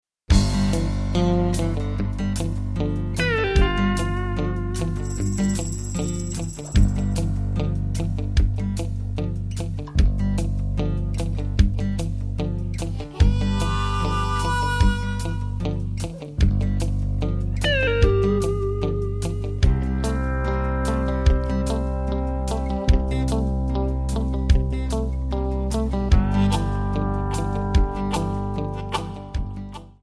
Backing track files: Country (2471)
Buy With Backing Vocals.